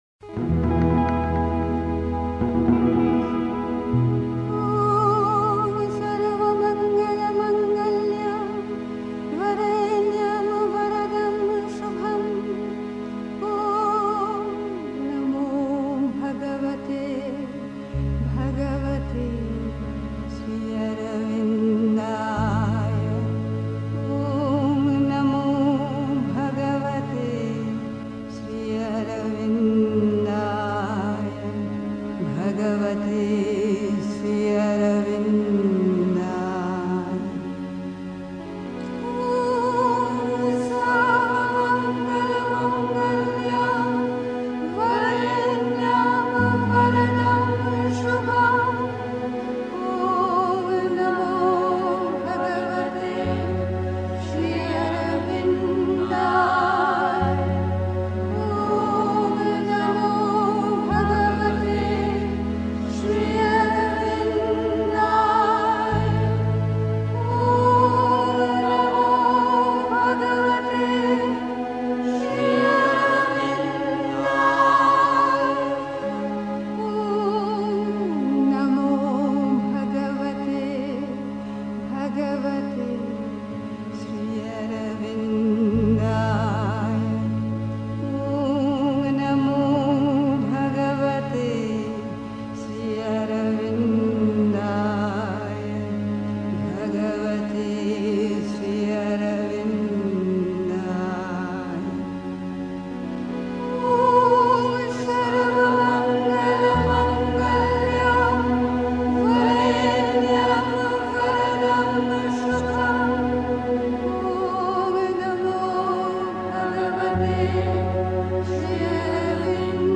Pondicherry. 2. Das wahre Mittel zum Erfolg in der Sadhana (Sri Aurobindo, CWSA Vol. 32, p. 298). 3. Zwölf Minuten Stille.